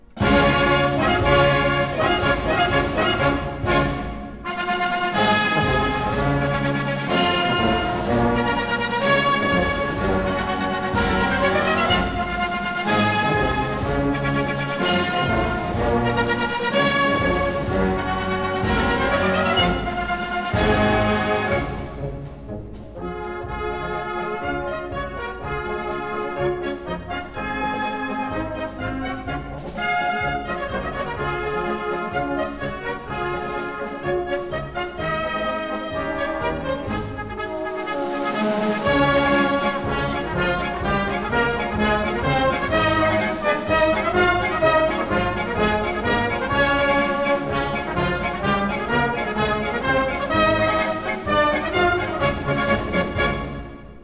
A 1959 stereo recording